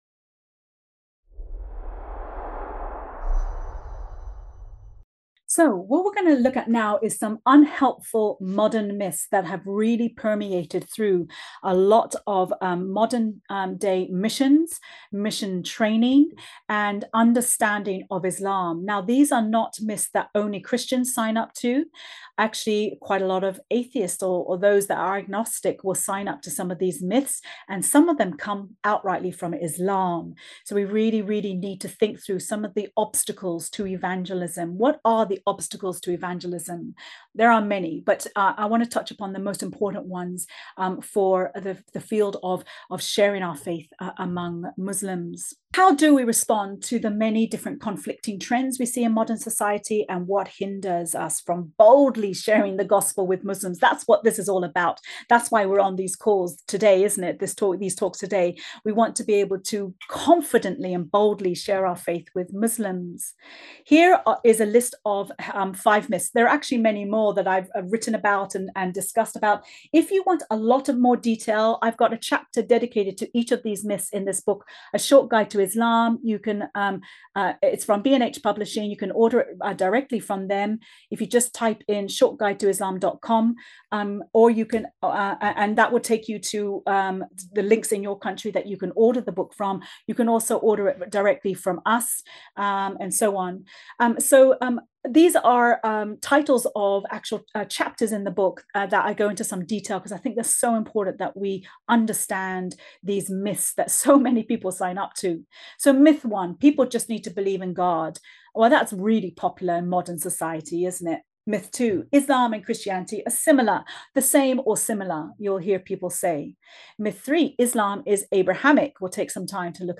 Master Class